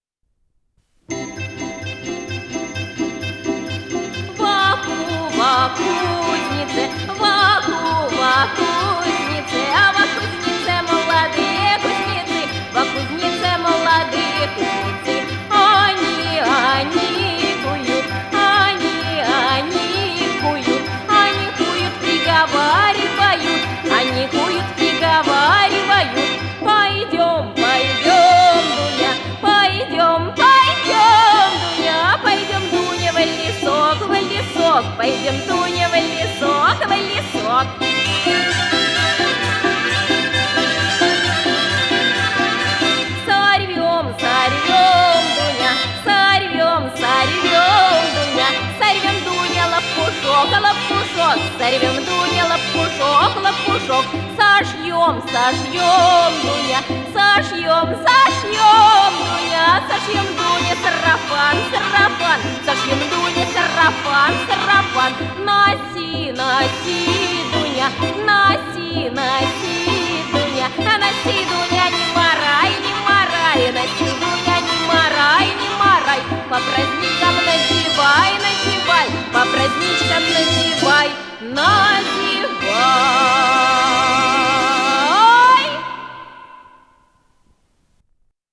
с профессиональной магнитной ленты
ИсполнителиЛюдмила Рюмина - пение
АккомпаниментОркестр народных инструментов "Русские узоры"
ВариантДубль моно